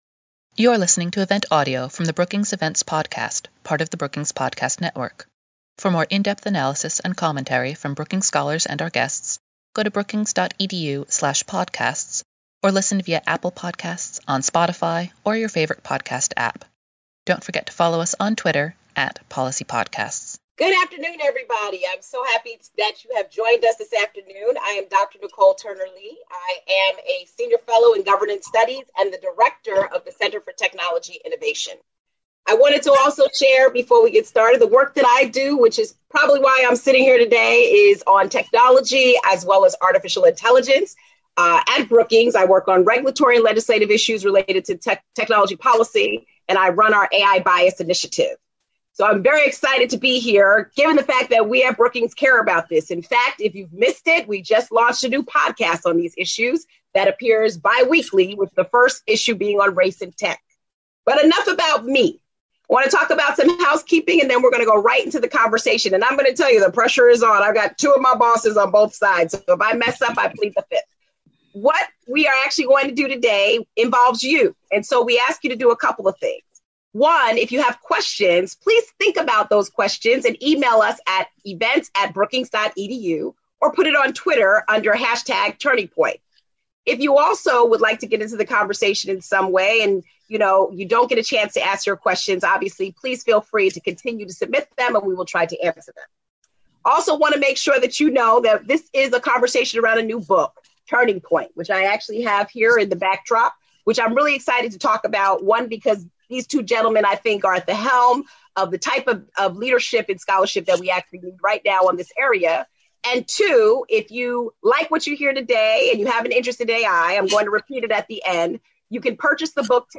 webinar discussion